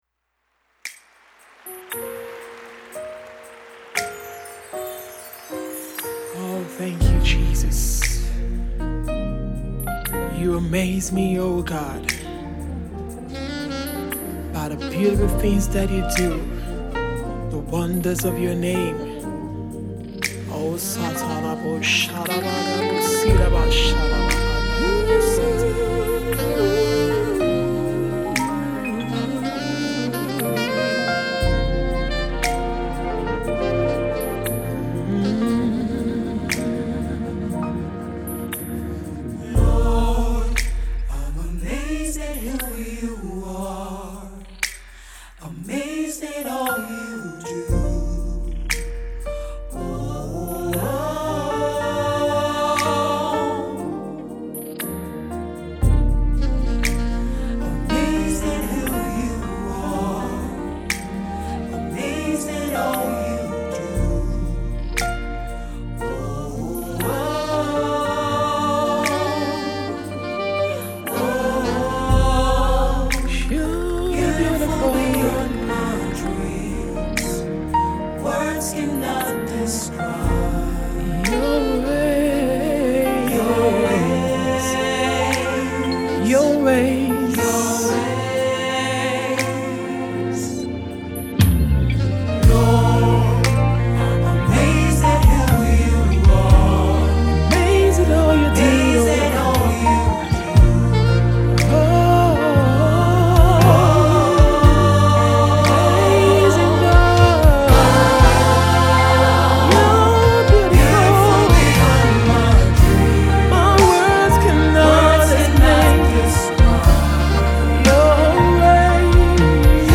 The contemporary Gospel singer slows down the tempo to pour
The rich composition is expressive
Her Western Contemporary style is influenced by the
Tags: Gospel Music,   Naija Music